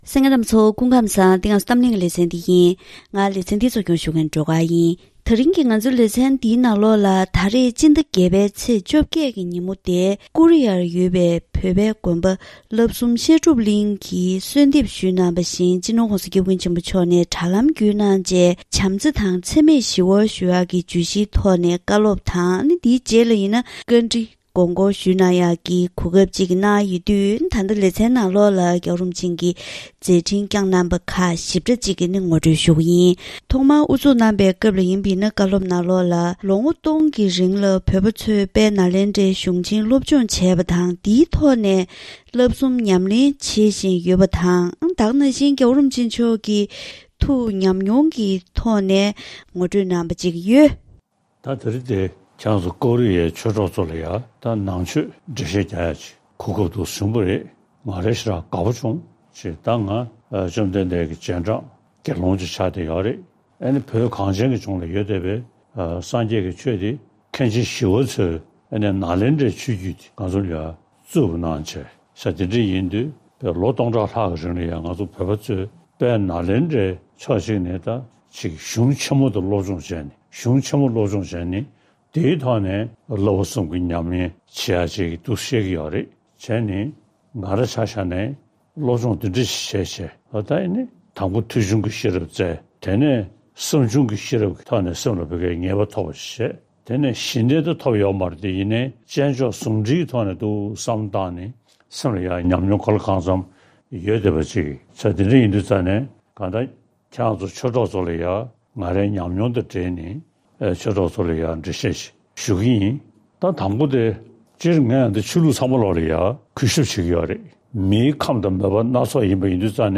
༧གོང་ས་༧སྐྱབས་མགོན་ཆེན་པོ་མཆོག་གིས་བཞུགས་སྒར་ཕོ་བྲང་ནས་དྲ་ལམ་བརྒྱུད་ཀོ་རི་ཡའི་དད་ལྡན་པ་ཚོར་བྱམས་བརྩེ་དང་འཚེ་མེད་ཞི་བའི་སྐོར་བཀའ་སློབ་བསྩལ་བ།